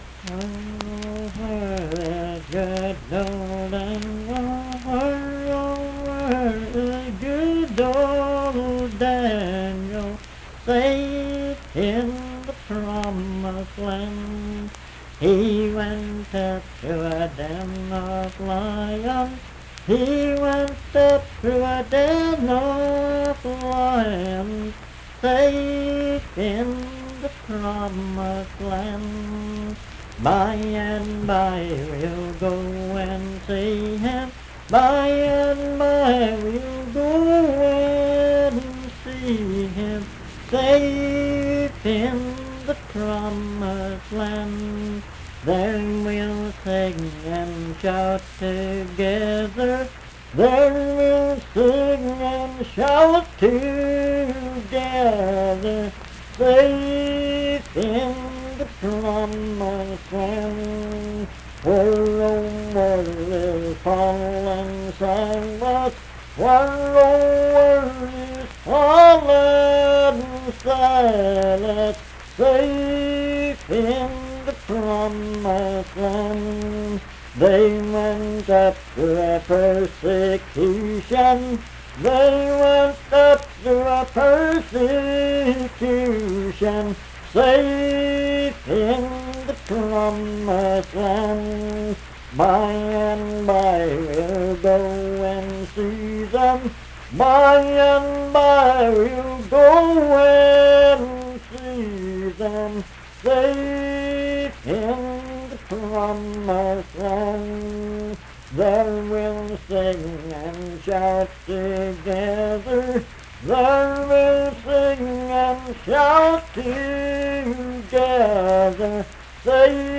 Unaccompanied vocal music
Verse-refrain 12(3w/R). Performed in Dundon, Clay County, WV.
Hymns and Spiritual Music
Voice (sung)